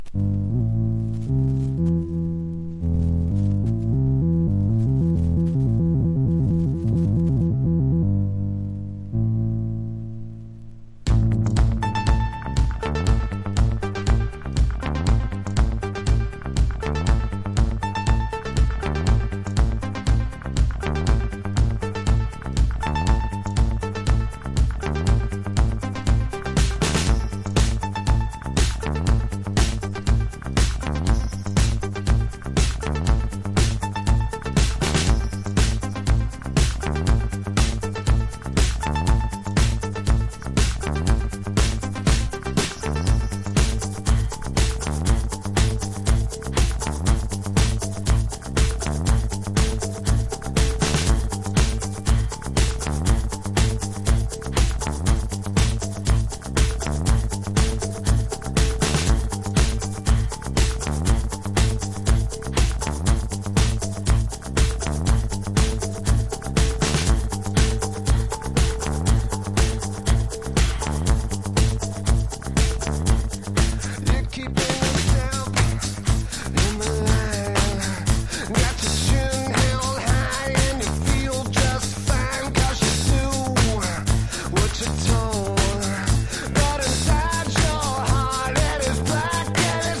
INDIE DANCE# ALTERNATIVE / GRUNGE